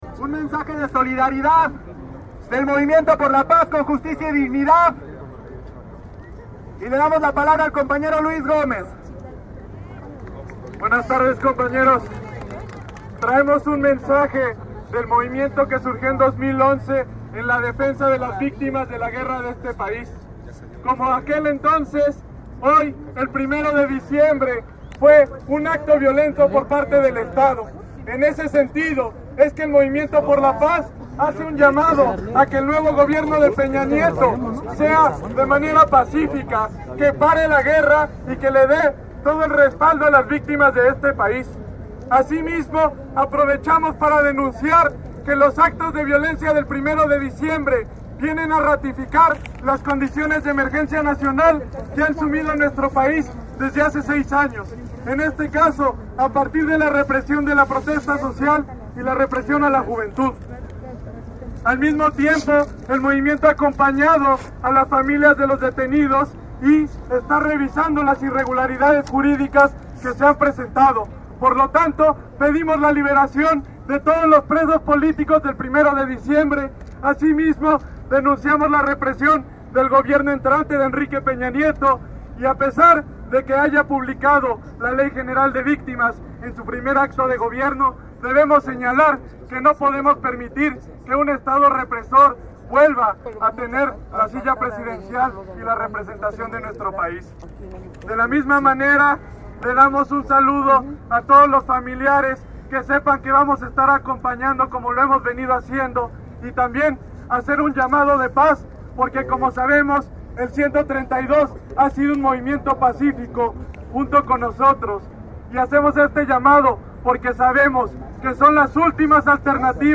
Durante el mitin en el zócalo se vivía un ambiente de tranquilidad, silencio y atención a las palabras emitidas a través del megáfono.Los presentes escuchaban con atención sentados desde sus lugares en la plancha del Zócalo.